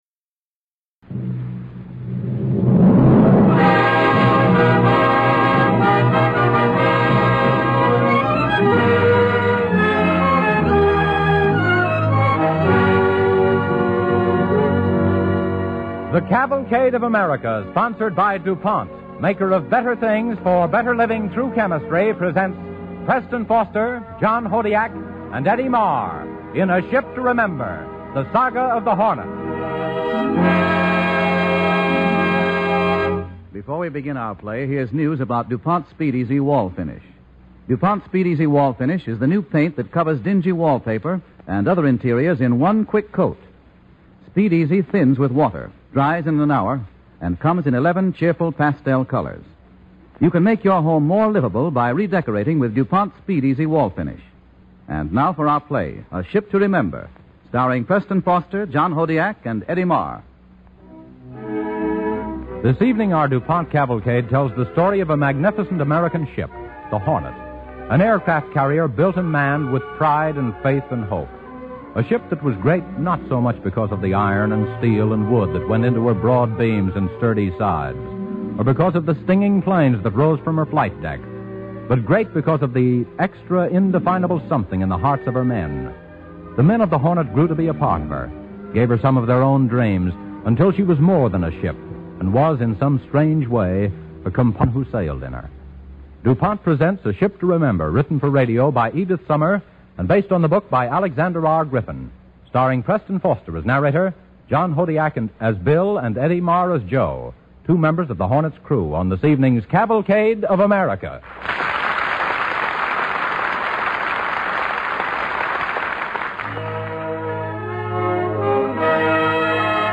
Listen to and download the Cavalcade of America Radio Program